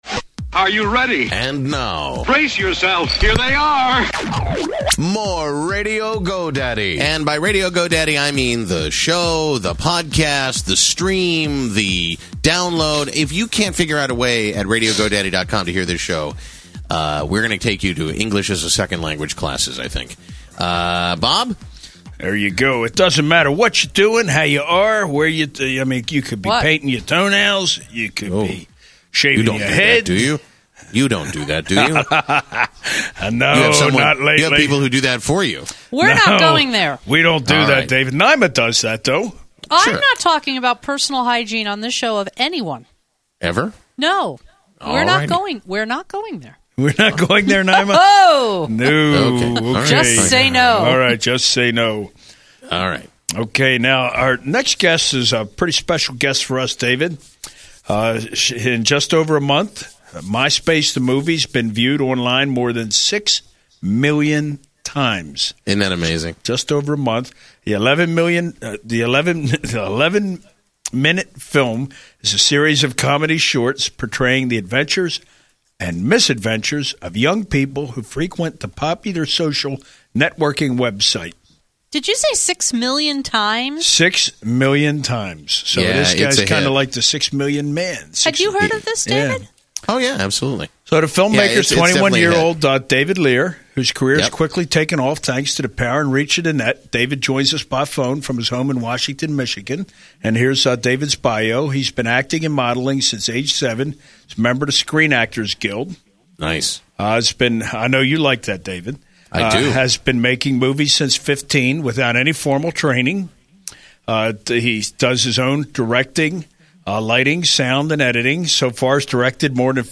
Radio Go Daddy Interview